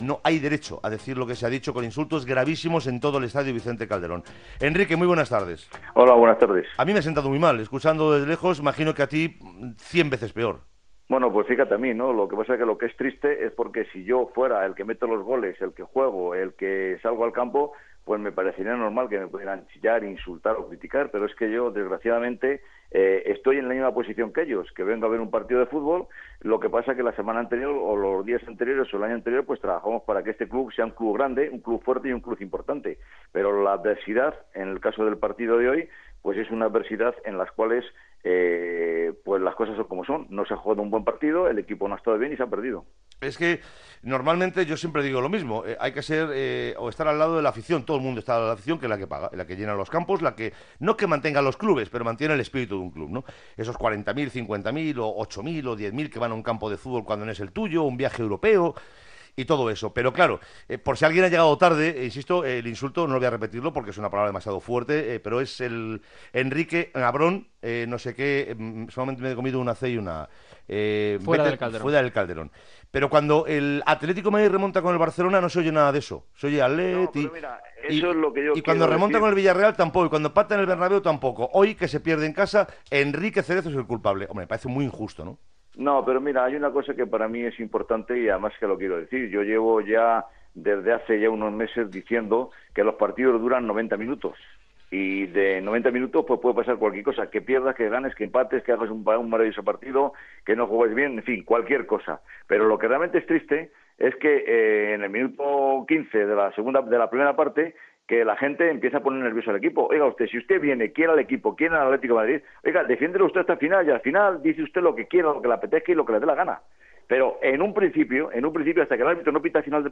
Entrevista al president de l'Atlético de Madrid que ha estat insultat aquella jornada en l'estadio Calderón en predre el seu equip
Esportiu